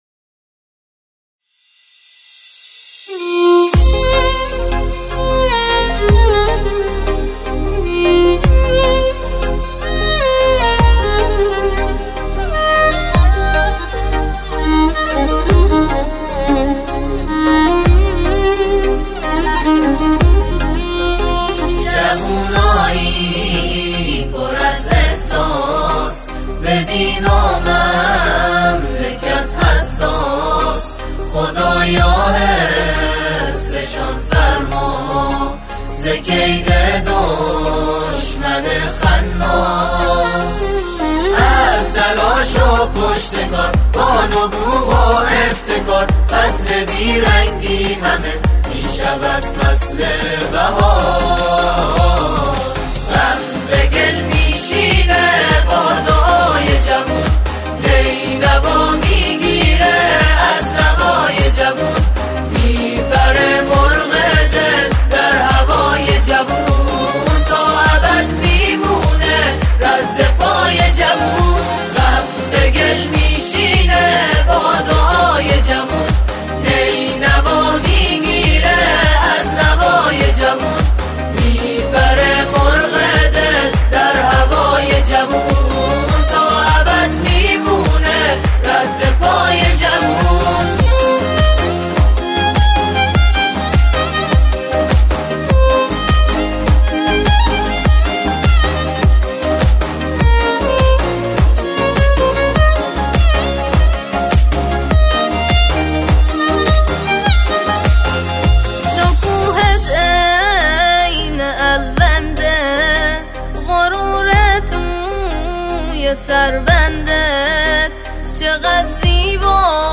استودیو حوزه هنری استان خراسان جنوبی